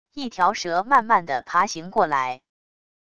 一条蛇慢慢的爬行过来wav音频